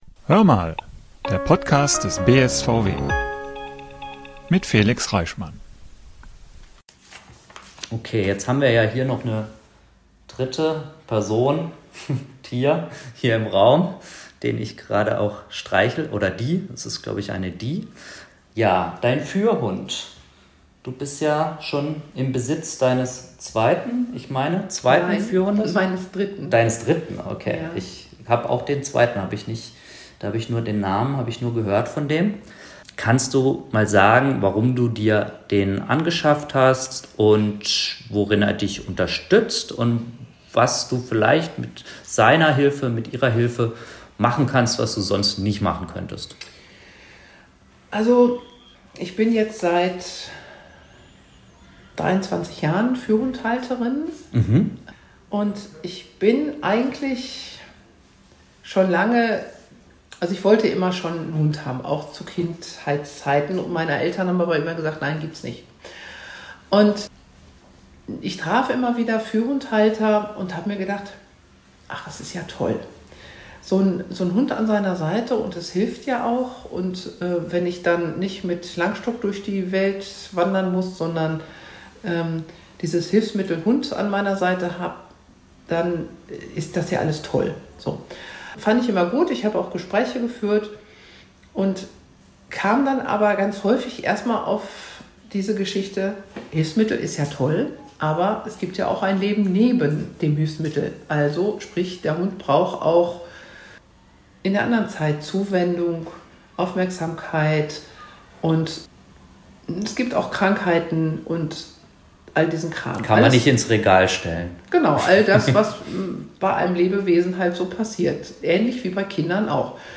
Im zweiten Teil unseres Gesprächs